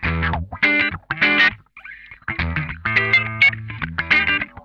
CRUNCHWAH 2.wav